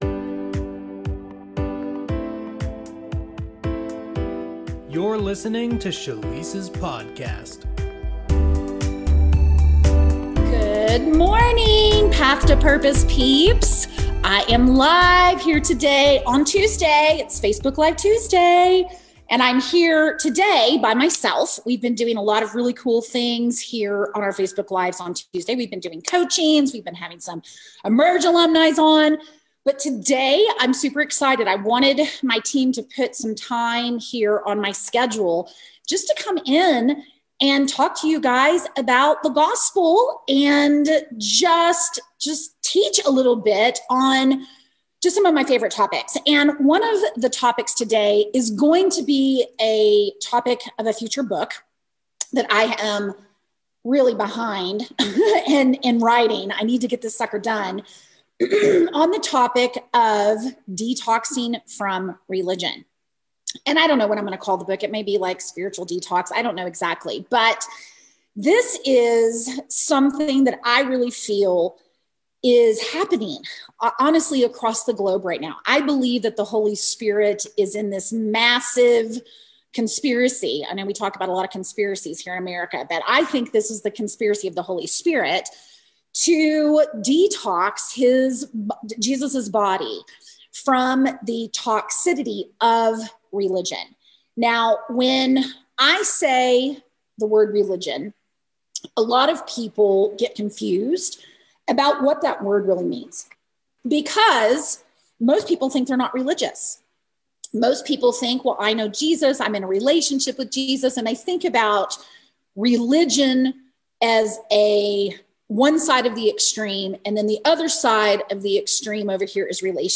I preached myself so happy here, so I wanted to share it!